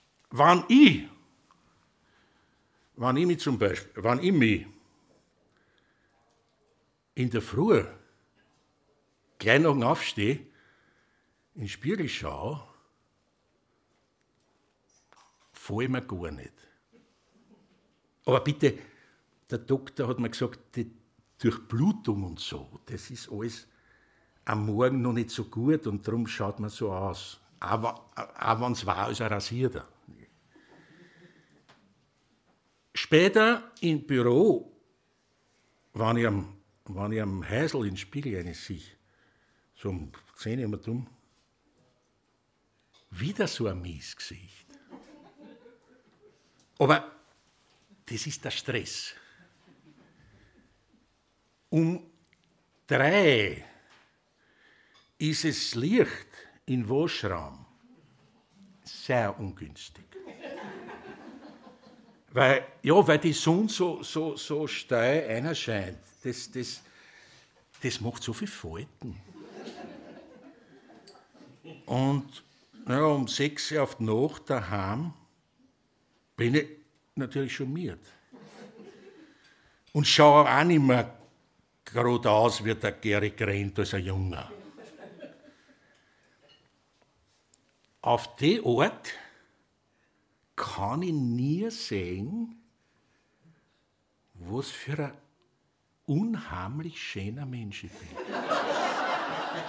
Audiodatei Download: audio/mpeg (aus dem Programm „Das ist klassisch!“): Drei Dialektgedichte von Herbert Pirker Ihr Browser unterstützt die html5 Audiowiedergabe nicht.